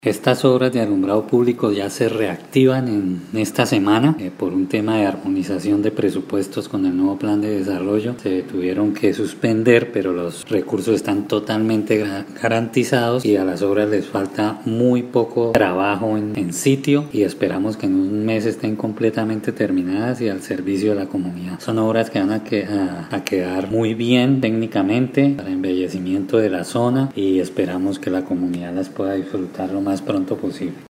Descargar audio: Iván Vargas, secretario de Infraestructura